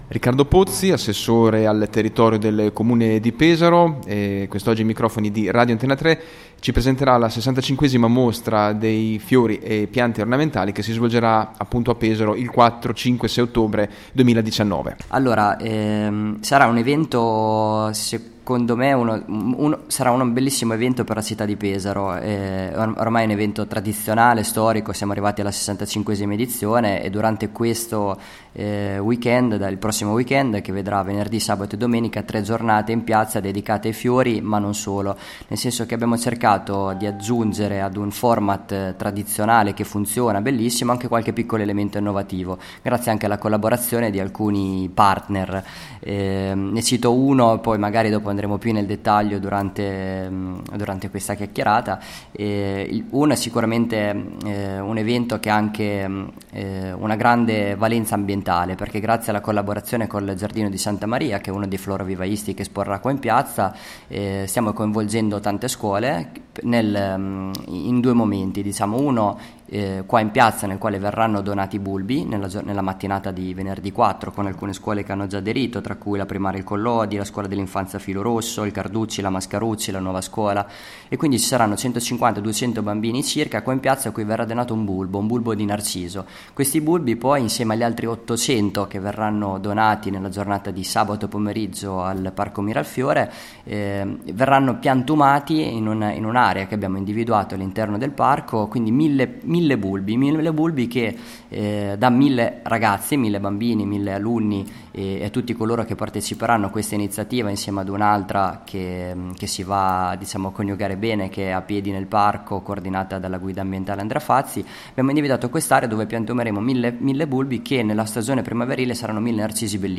65° Mostra Mercato Dei Fiori: Intervista All’Assessore Pozzi